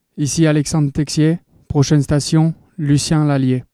Les joueurs des Canadiens ont prêté leurs voix à la Société de transport de Montréal (STM) pour annoncer les arrêts sur la ligne orange à proximité du Centre Bell.